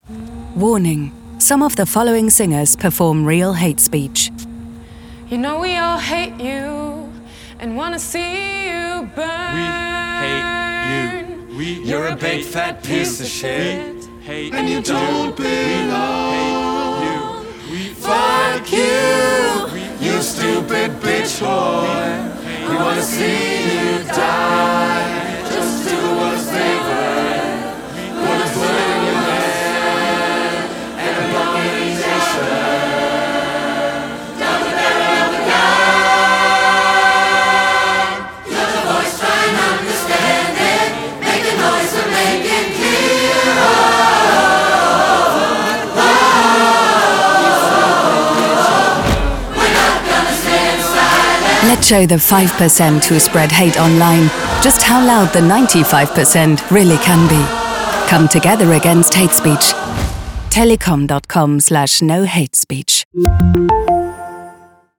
Une « chorale contre les discours de haine » pour dénoncer la banalisation des propos haineux.
The-choir-against-hate-speech.mp3